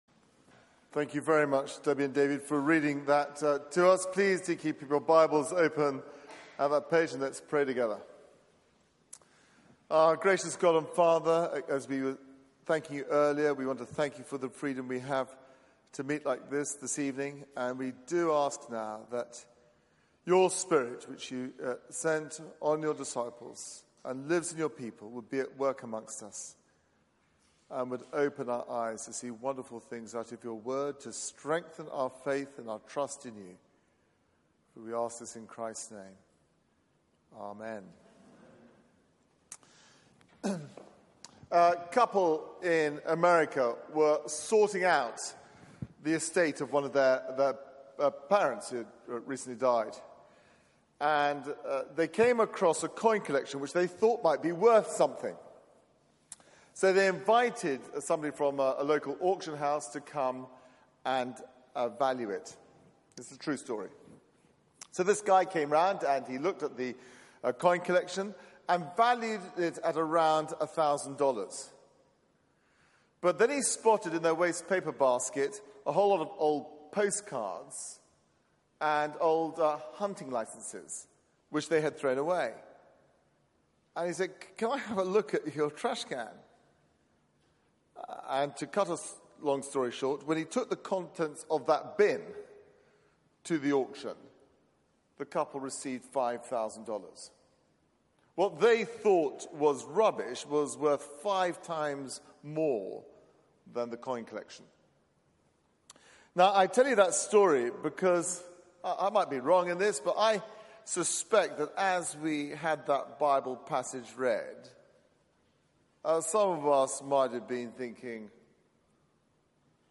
Media for 6:30pm Service on Sun 10th Jun 2018 18:30 Speaker
Sermon